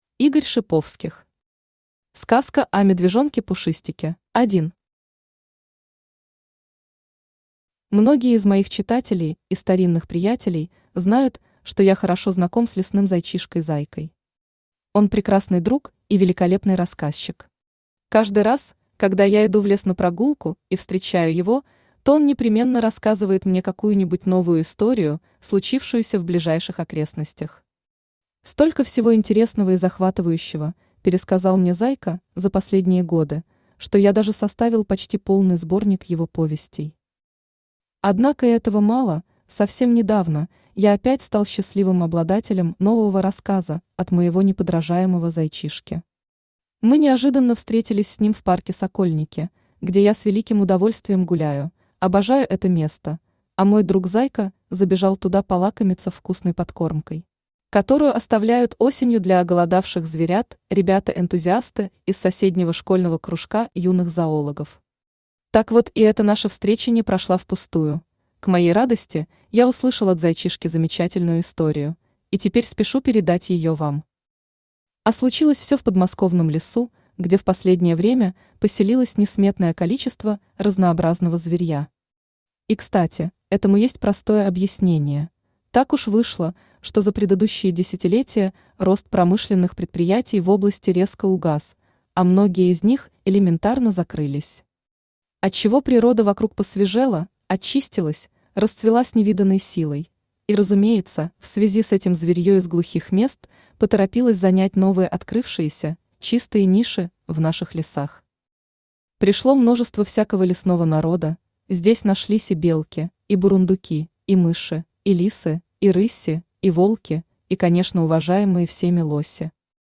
Аудиокнига Сказка о медвежонке Пушистике | Библиотека аудиокниг
Aудиокнига Сказка о медвежонке Пушистике Автор Игорь Дасиевич Шиповских Читает аудиокнигу Авточтец ЛитРес.